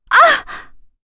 Epis-Vox_Damage_01.wav